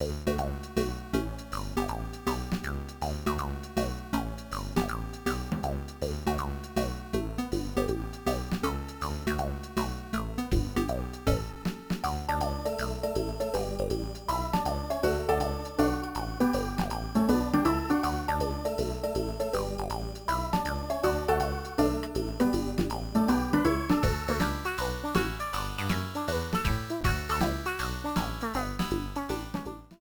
Self-recorded from emulator